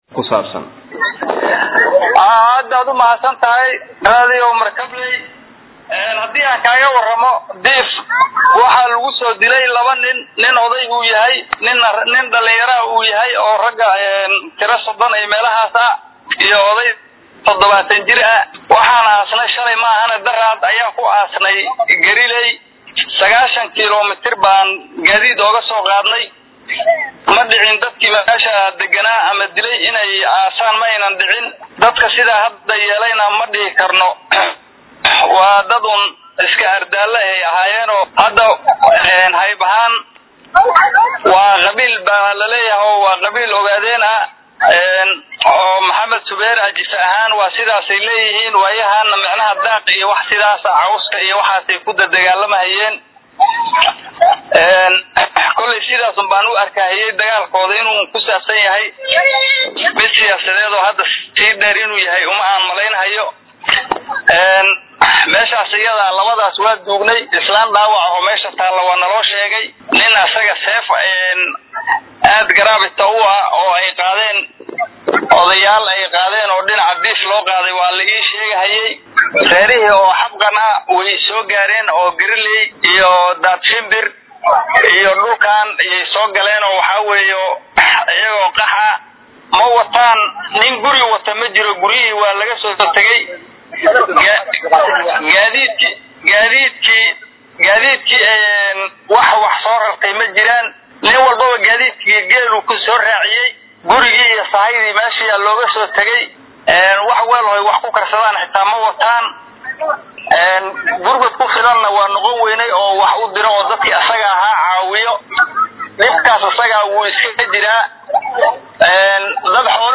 waraysi-garileey-ok.mp3